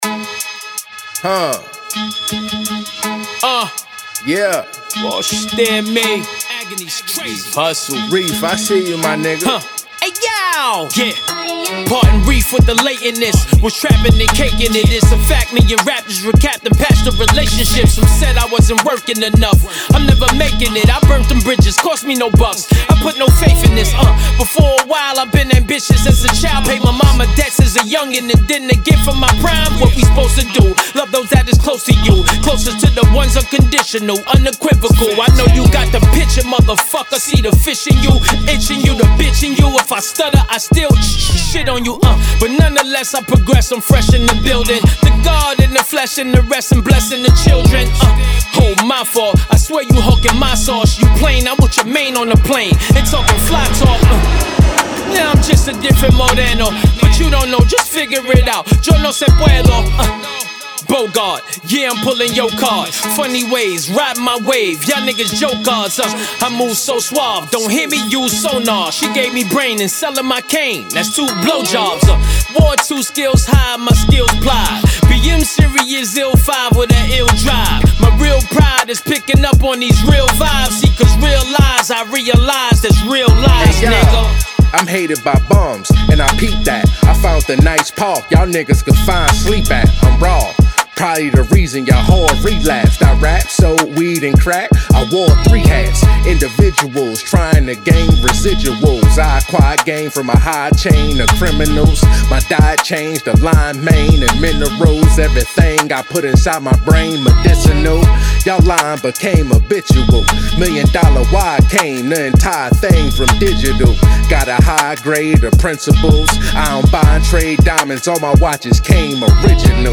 Emcee